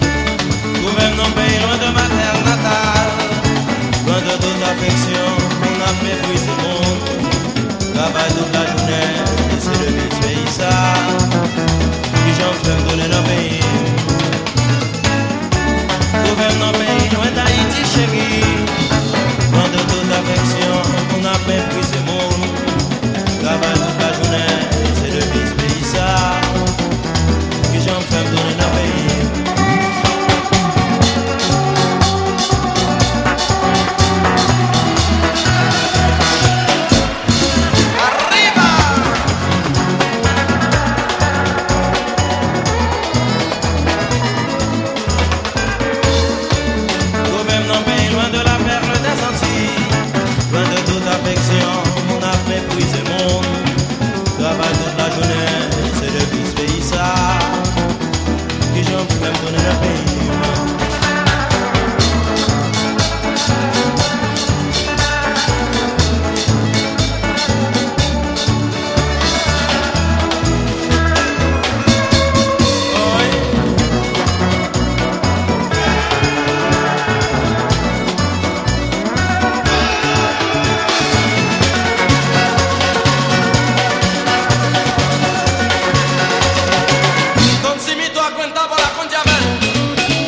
WORLD / OTHER / AFRICA / ARAB / LATIN / CUMBIA
最高！アフリカン・スウィング、バルカン・ブラス・バンド、ムビラ・ファンクなどを収録のワールド・ミュージック・オムニバス！